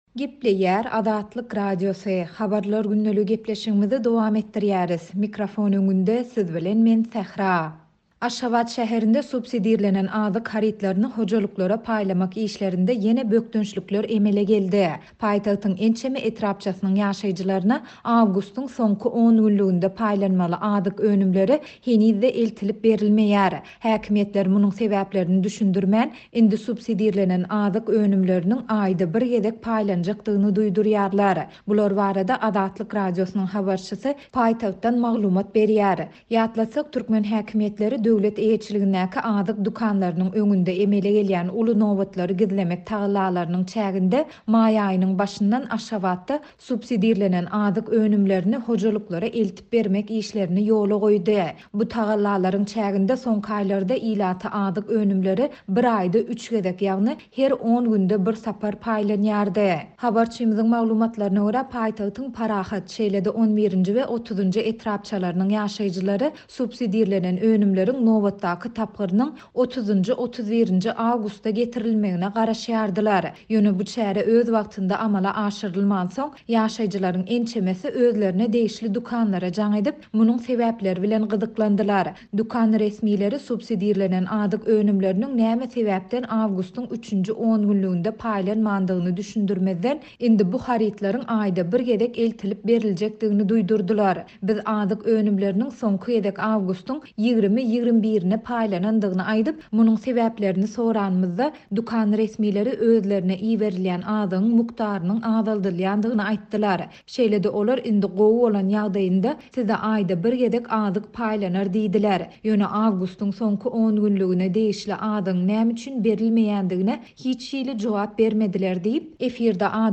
Häkimiýetler munuň sebäplerini düşündirmän, indi subsidirlenen azyk önümleriniň aýda bir gezek paýlanjakdygyny duýdurýarlar. Bular barada Azatlyk Radiosynyň habarçysy paýtagtdan maglumat berýär.